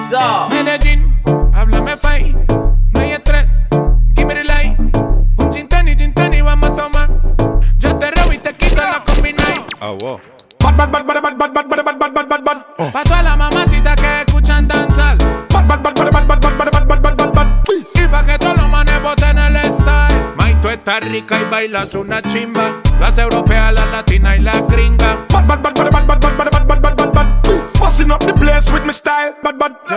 Off-beat гитары и расслабленный ритм
Modern Dancehall Reggae
Жанр: Регги